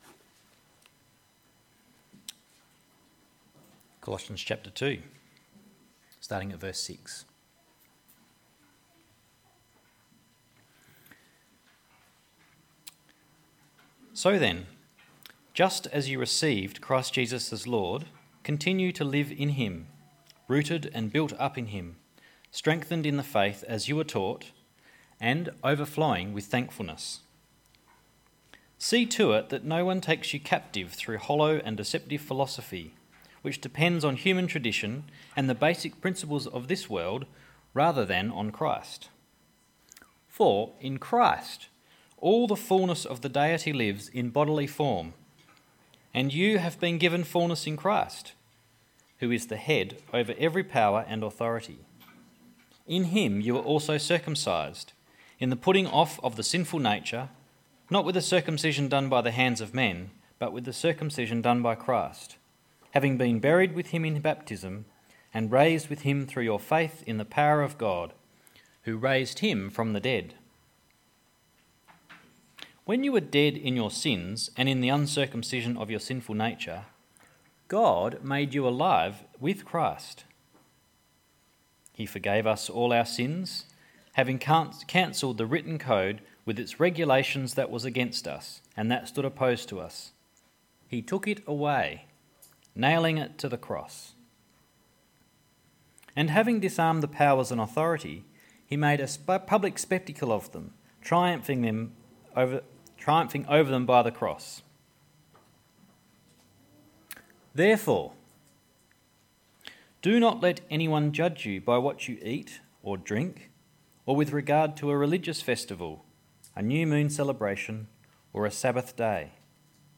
3 February 2019 Christ is Enough Passage: Colossions 2: 6-19 Service Type: Sunday AM Bible Text: Colossions 2: 6-19 | ‘The World’ tries to tell us what to do.